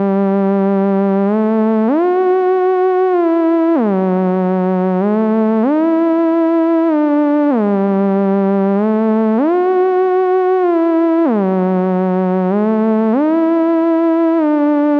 Music sequencing test with , , and